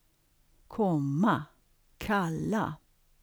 KAPITEL 10 - DANSK FONOLOGI 10.1: svensk [ˈkʰom:a ˈkʰal:a]
10.1-svensk-komma-kalla.wav